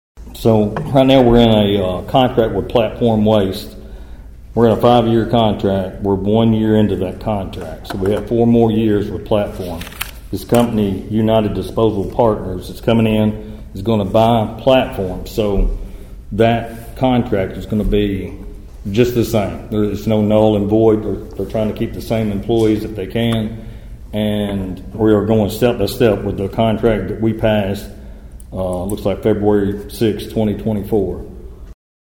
During the latest Council meeting, City Manager Johnny McTurner explained the change over from Platform Waste Solutions.(AUDIO)